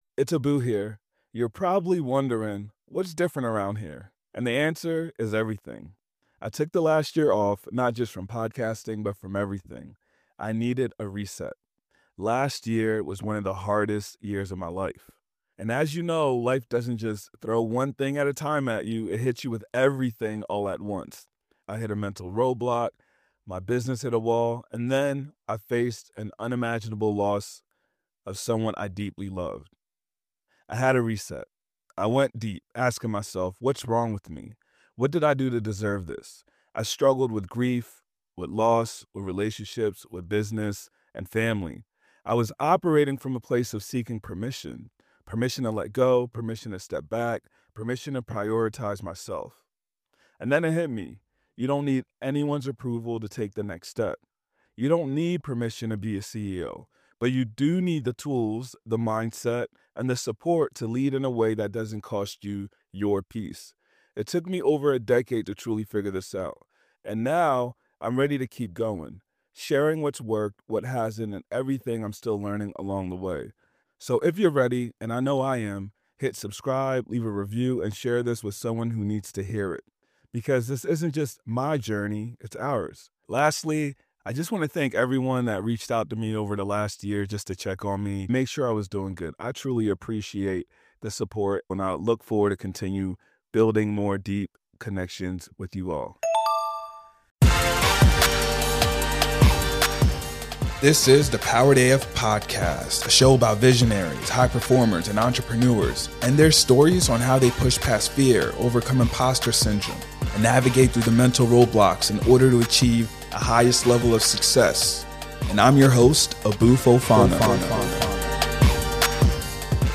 We go over these changes and also discuss how to best position yourself in this ever-changing digital space.